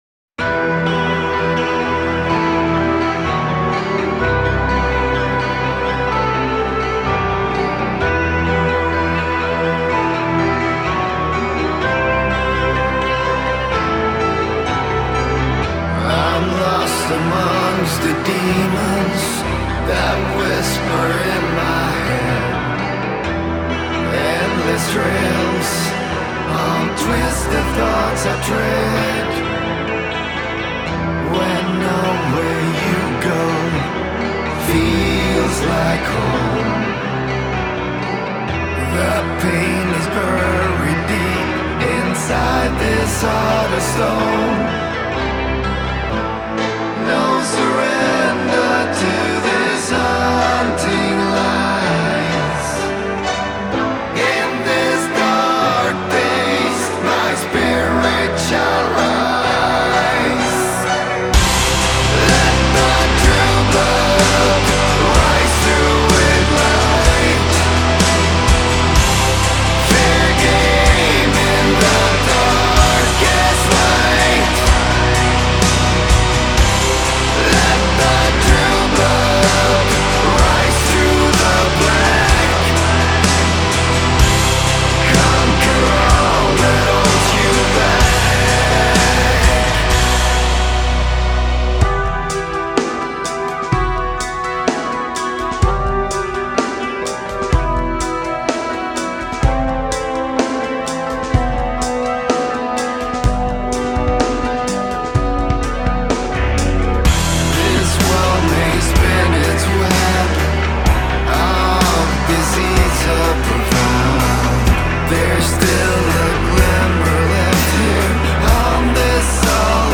• Жанр: Metal